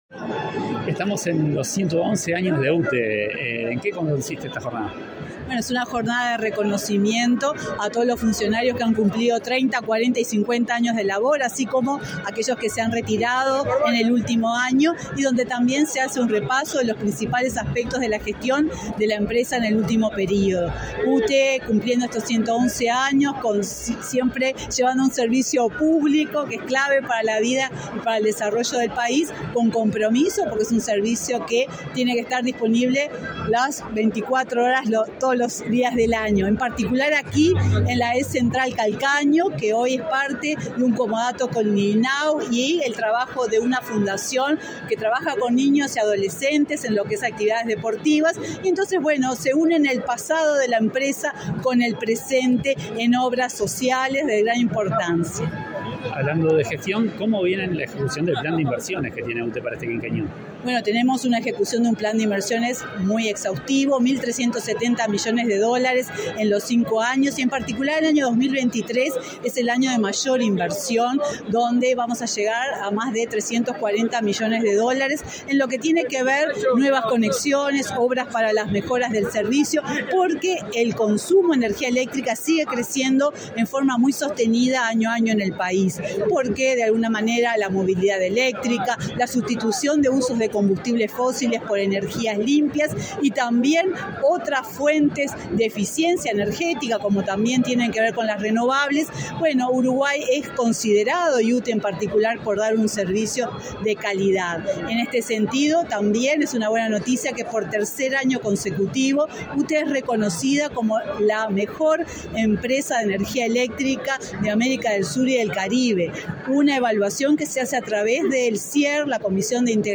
Entrevista a la presidenta de UTE, Silvia Emaldi
La UTE realizó, este 24 de octubre, el acto conmemorativo por su 111.° aniversario.